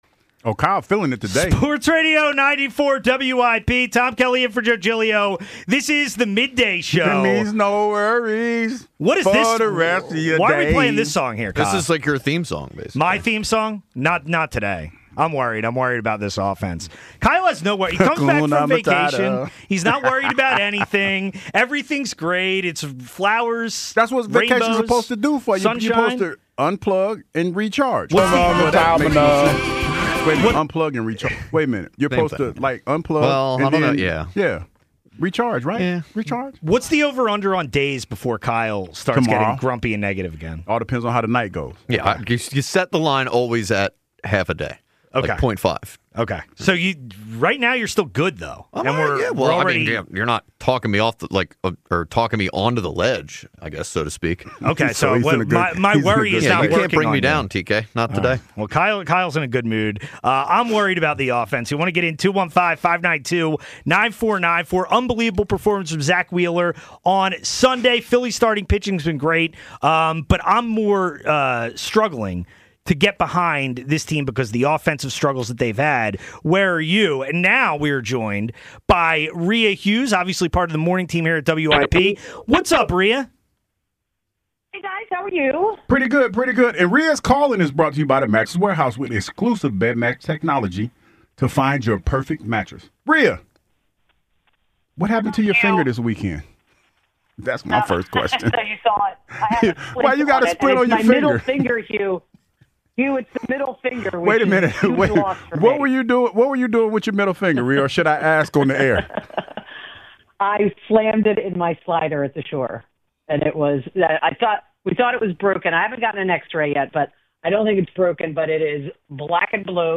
calls into the midday show to give her take on the Phillies over the long weekend!